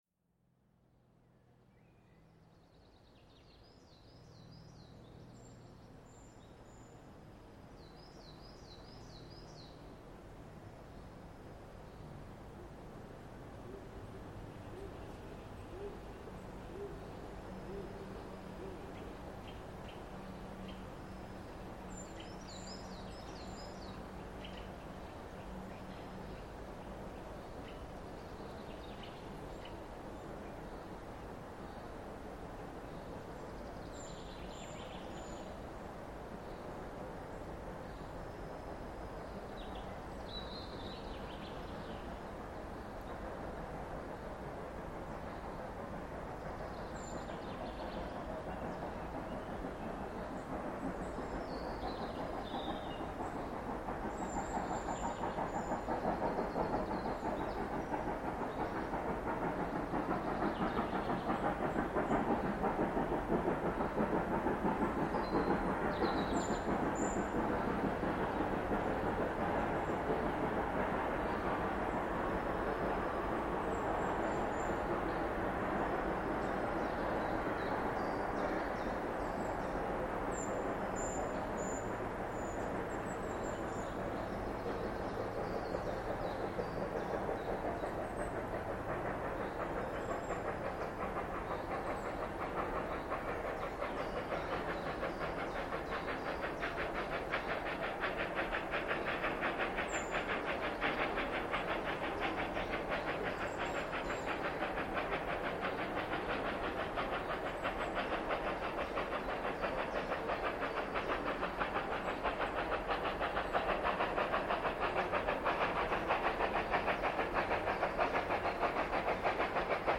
Erstaunlicherweise konnten alle 4 Züge hoch nach Oppingen ganz ohne nervige, akustische Störungen eingefangen werden und die Lok wurde wieder im Unterschied zu den Vorjahren anständig laut und hart gefahren.
99 7203  mit 1.Zug Amstetten→Oppingen mitten im Wald mittleres Duital, von dicht am bzw. über dem Gleis aus aufgenommen, um 10:10h am Sonntag, 18.05.2025.   Hier anhören: